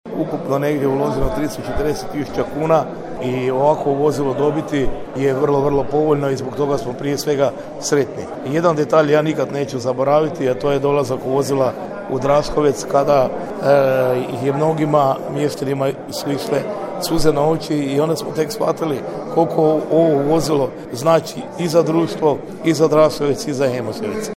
Gradonačelnik Kolarek: